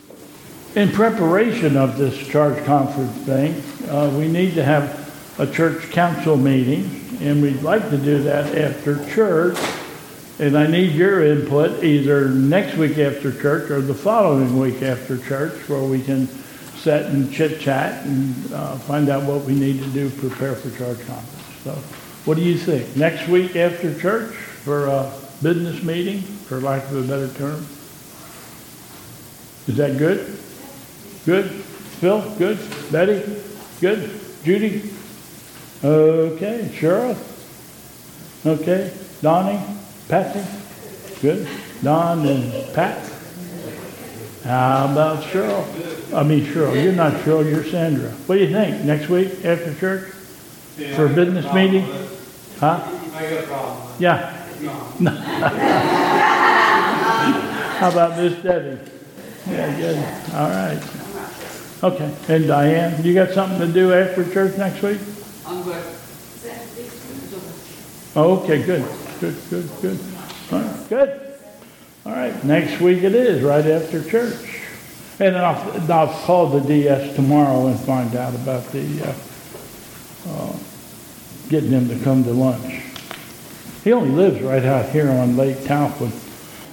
2022 Bethel Covid Time Service